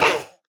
Minecraft Version Minecraft Version 1.21.4 Latest Release | Latest Snapshot 1.21.4 / assets / minecraft / sounds / mob / armadillo / hurt1.ogg Compare With Compare With Latest Release | Latest Snapshot
hurt1.ogg